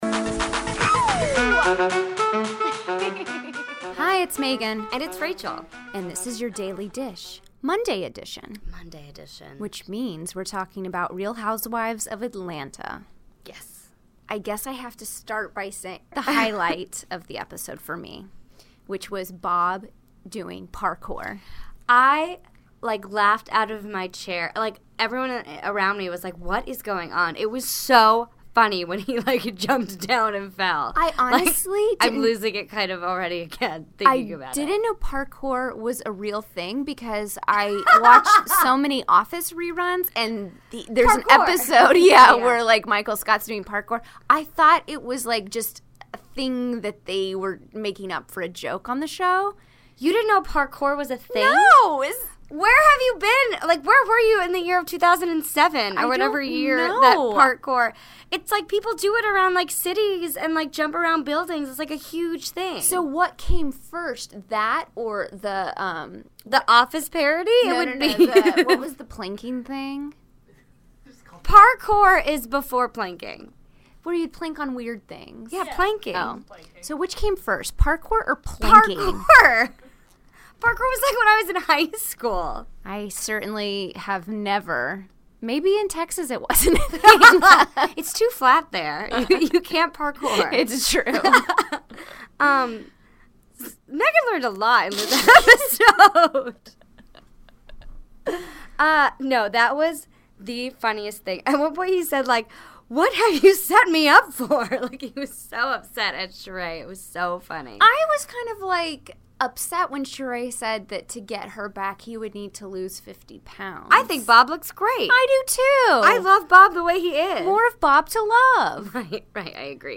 Monday, January 9, 2017 - From Bravo HQ in New York City, we're talking about last night's episode of The Real Housewives of Atlanta. Find out more about Shereé's book, Bob's parkour career, and that wild night at Club One.